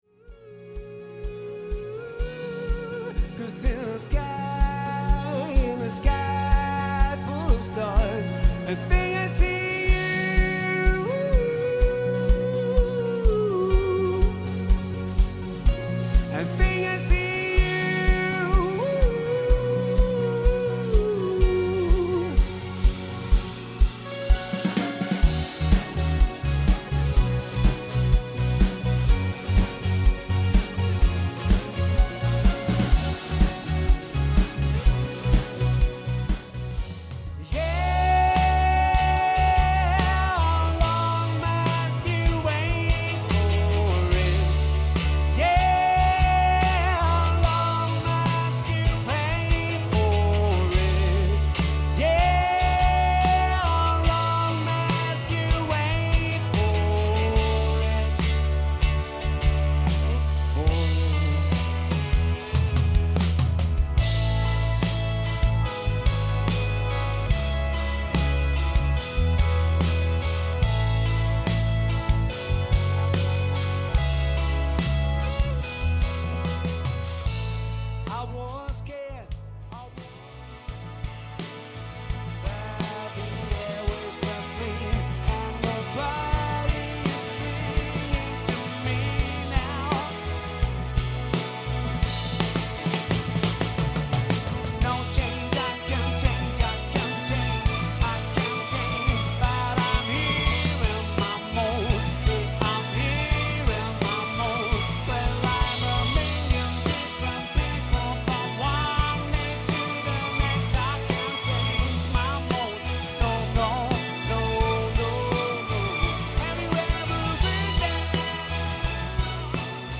5-köpfige Cover-Band
spherisch-melodische Sounds
VOCALS
KEYS
GITARRE
BASS